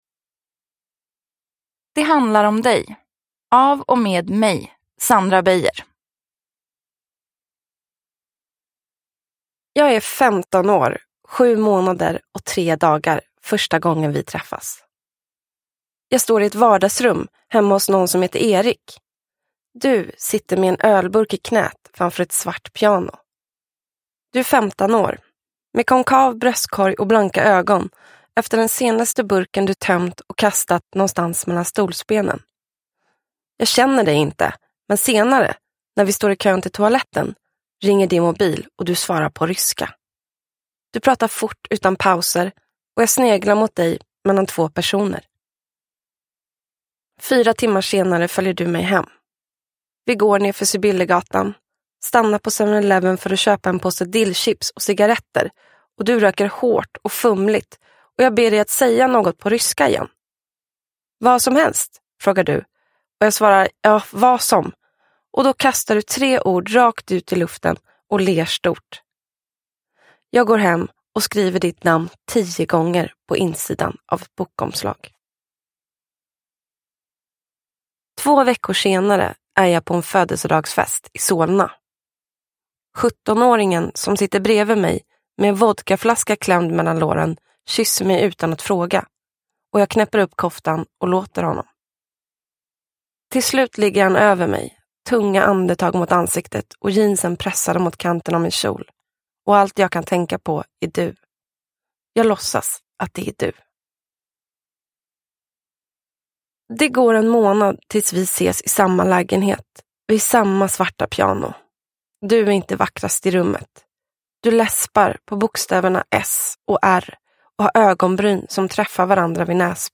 Det handlar om dig – Ljudbok – Laddas ner